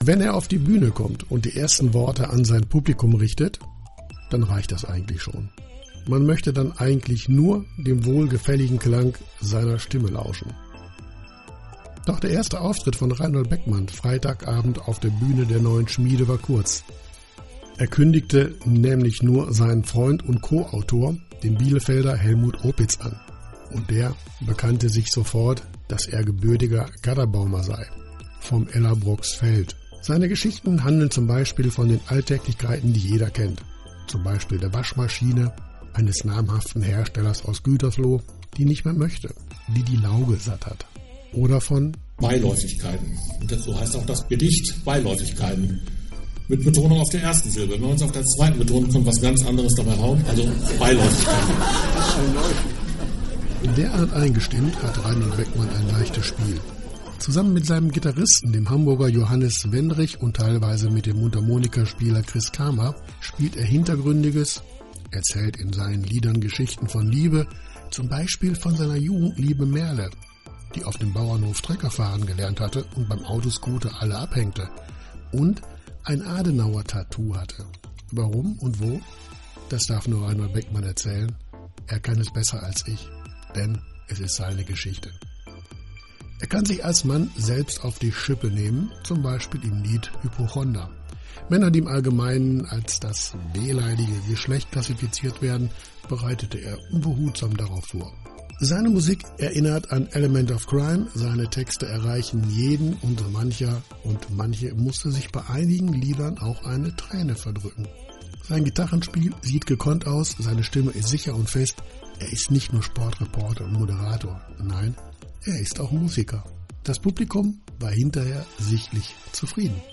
Und jetzt hat Reinhold Beckmann die Bühne und die Muik für sich entdeckt. Freitag, den 31. März 2023 gastierte er mit seiner Band in der Neuen Schmiede.